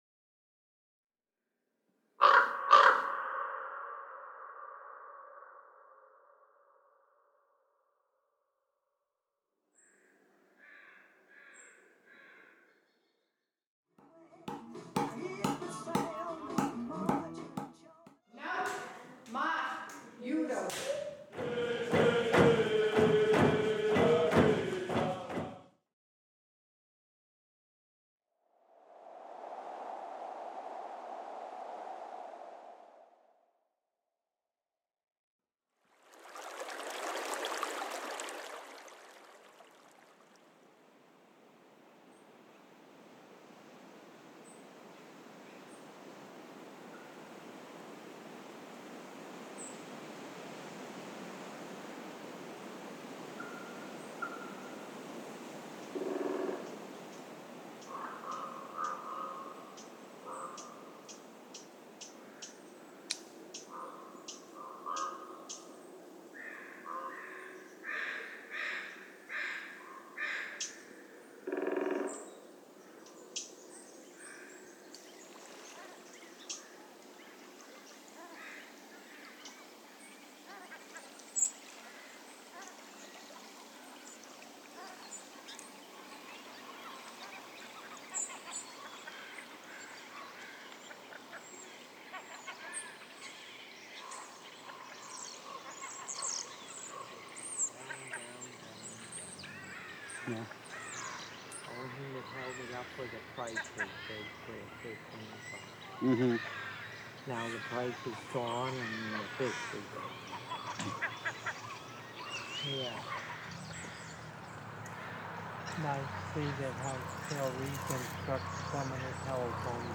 A Soundscape Composition
A raven croaks.